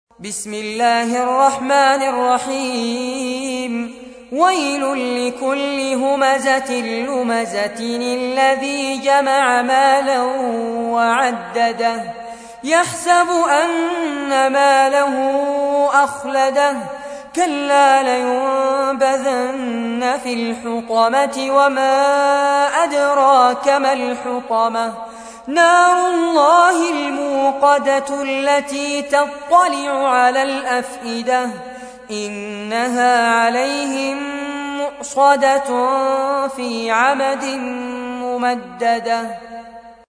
تحميل : 104. سورة الهمزة / القارئ فارس عباد / القرآن الكريم / موقع يا حسين